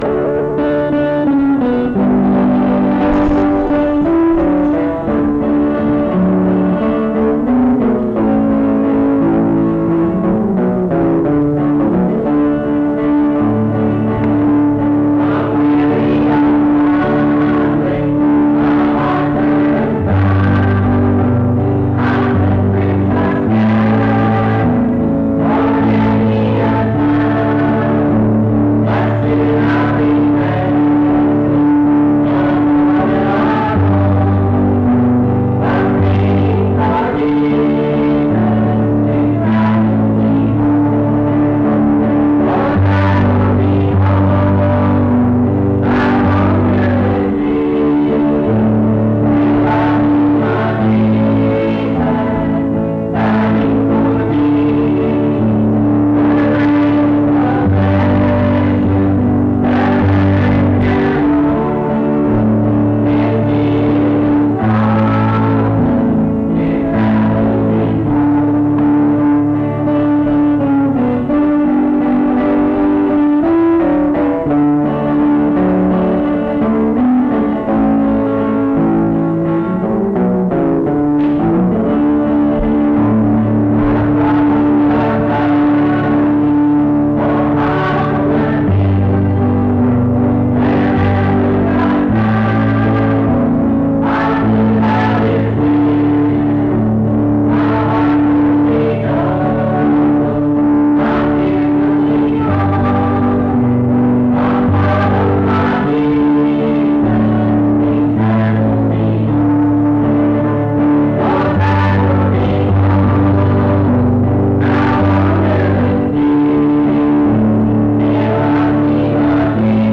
I'm Travelling Home Item 48ef8269f9a27284b4c2809c674fef4d1fd65caa.mp3 Title I'm Travelling Home Creator Mount Union I Choir Description This recording is from the Monongalia Tri-District Sing.